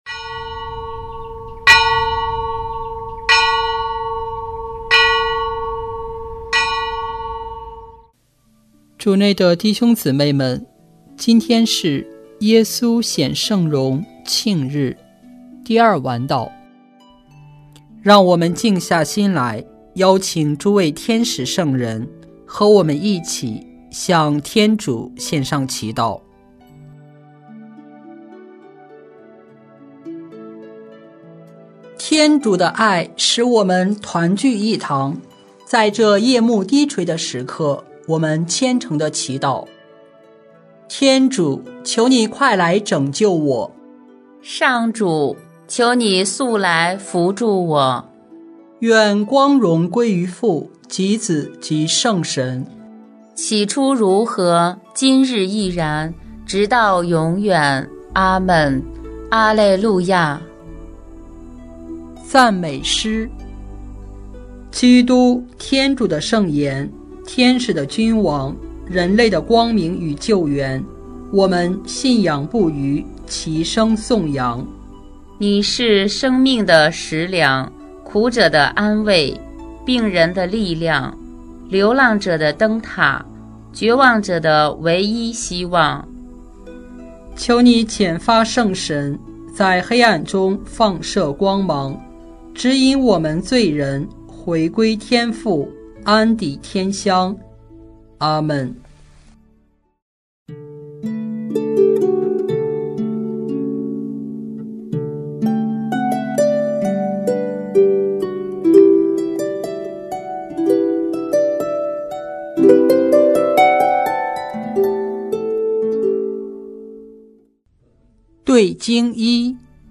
【每日礼赞】|8月6日耶稣显圣容庆日第二晚祷